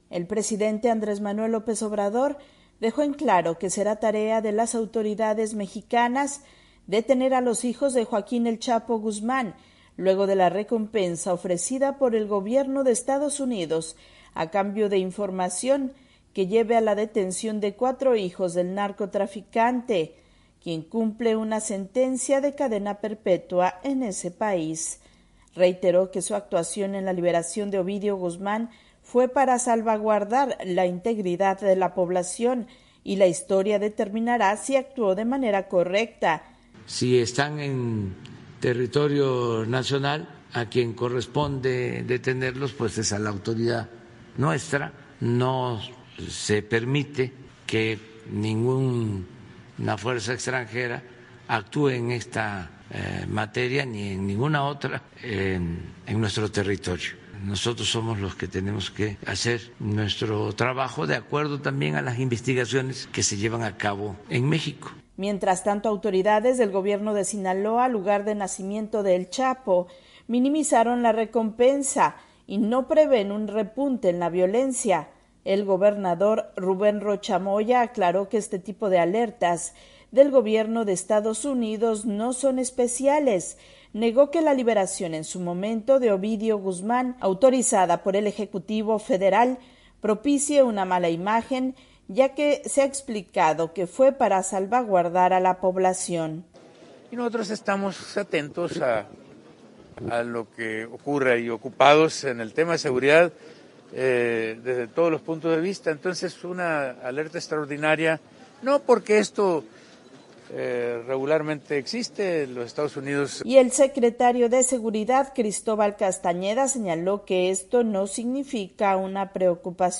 Tras la recompensa ofrecida por el gobierno de Estados Unidos a cambio de información que lleve a la captura de los hijos del narcotraficante Joaquín el Chapo Guzmán, el presidente de México dijo que corresponde a su gobierno la detención. Informa la corresponsal de la Voz de América